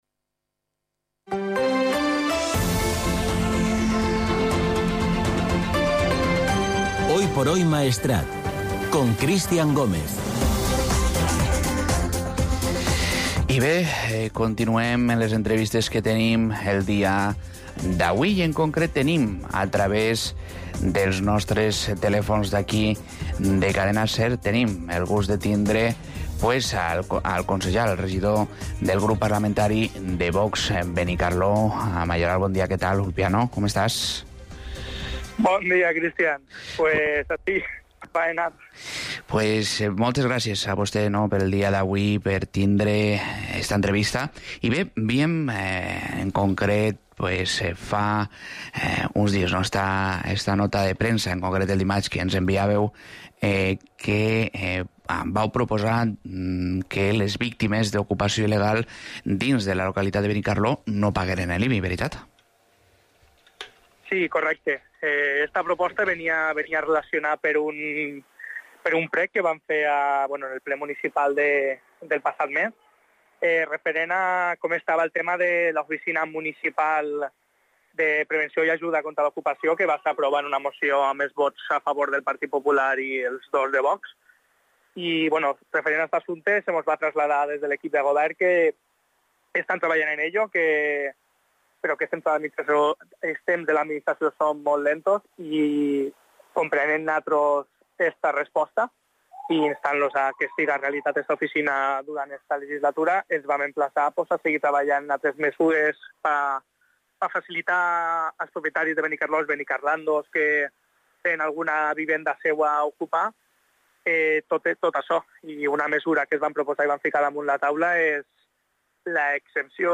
Podcast | Entrevista a Ulpiano Mayoral regidor del grup municipal VOX de Benicarló